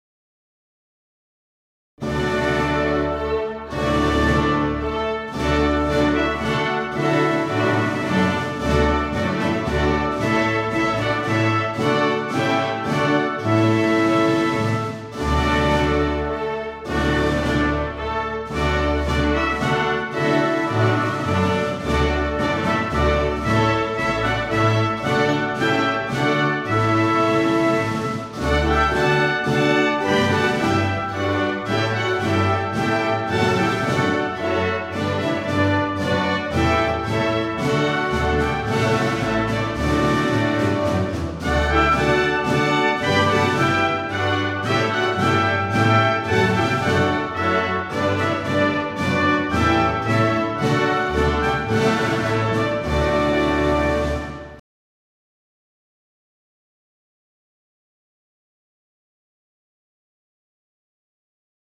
Minueto Haendel.mp3